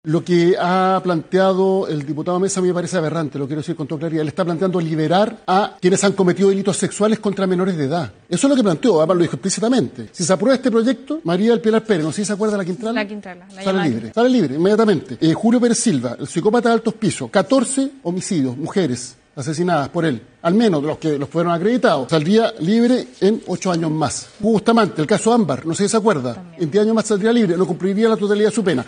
Durante su participación en el programa Estado Nacional, Elizalde cuestionó duramente que la medida incluya a condenados por delitos sexuales contra menores de edad y crímenes de alta connotación pública.